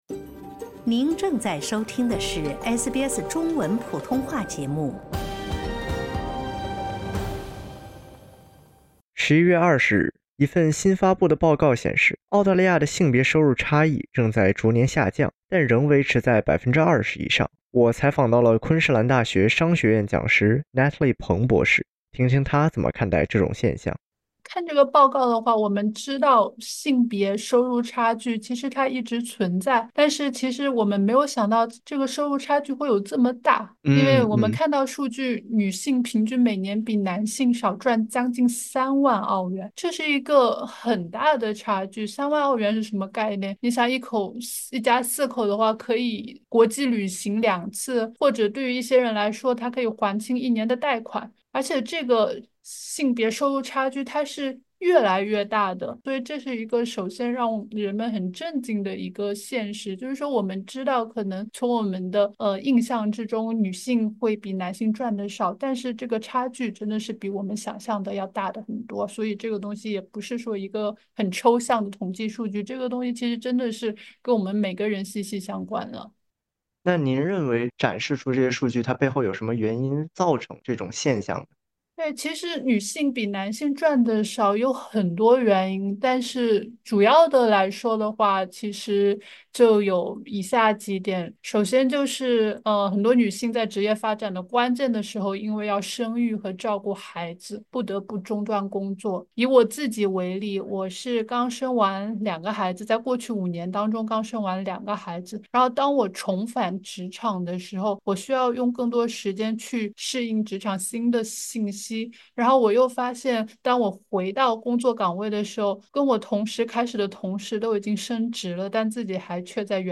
（点击音频，收听详细报道）